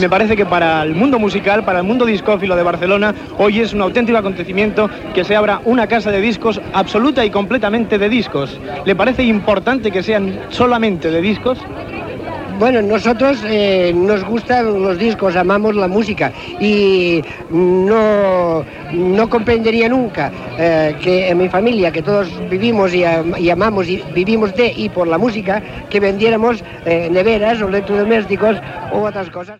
Reportatge sobre la primera botiga de Barcelona que ven únicament discs de vinil.
Informatiu
Extret de Crònica Sentimental de Ràdio Barcelona emesa el dia 29 d'octubre de 1994